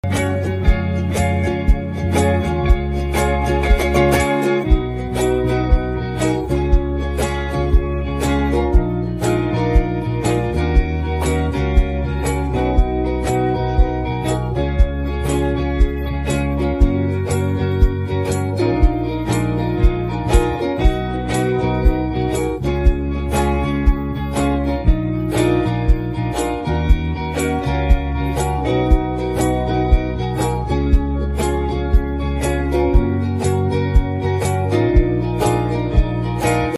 soothing instrumental version